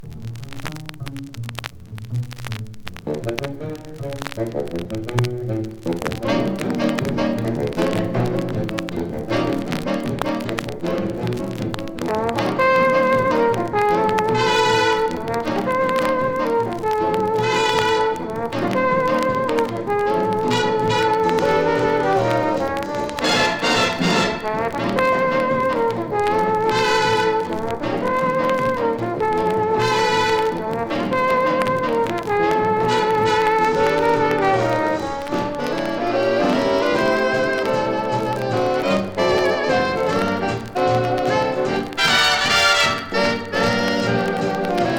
Jazz　USA　12inchレコード　33rpm　Mono
ジャケ擦れ汚れ背ダメージ　盤キズ多チリノイズ多